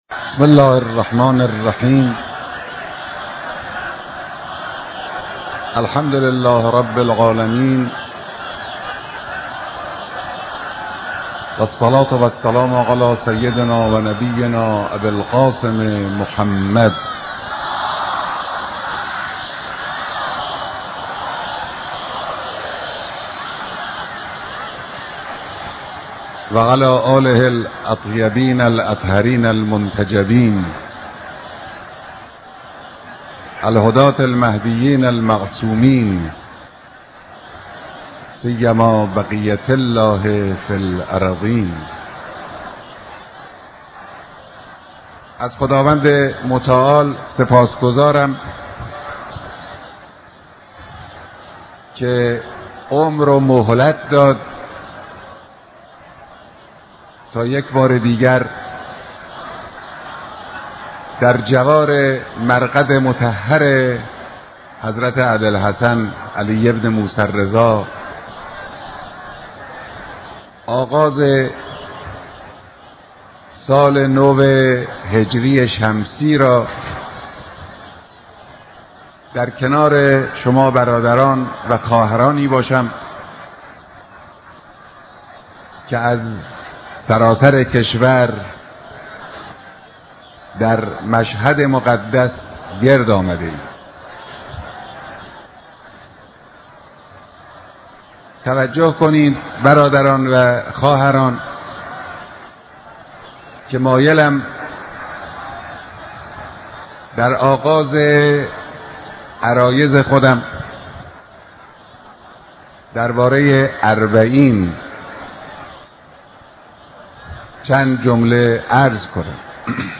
اجتماع عظيم ده‌هاهزار تن از زائران و مجاوران حرم مطهر حضرت امام رضا (ع)